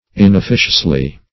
inofficiously - definition of inofficiously - synonyms, pronunciation, spelling from Free Dictionary Search Result for " inofficiously" : The Collaborative International Dictionary of English v.0.48: Inofficiously \In`of*fi"cious*ly\, adv. Not officiously.